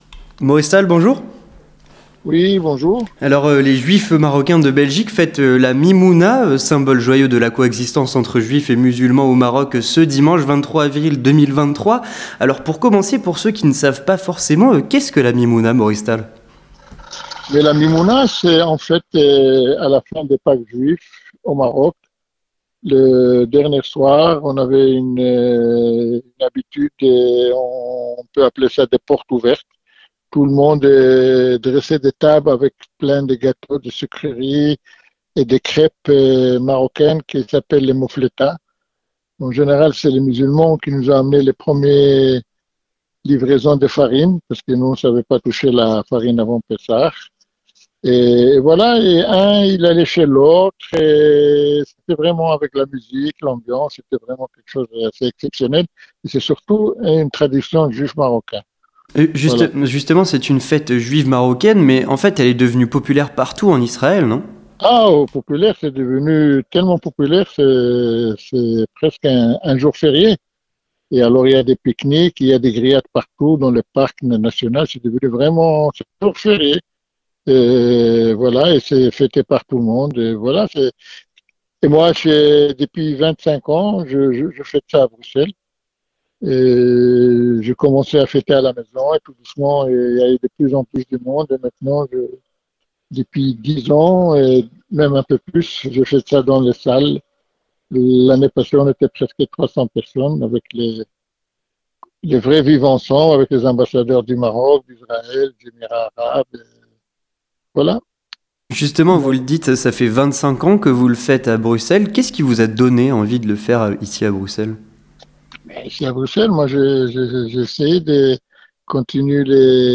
Interview Communautaire - La mimouna de coexistence à Bruxelles le 23/04